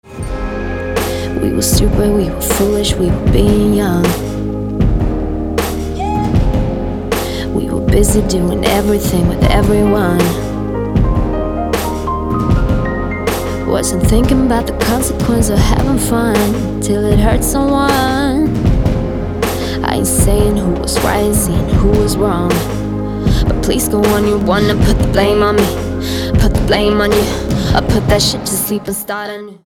• Качество: 256, Stereo
поп
женский вокал
dance
vocal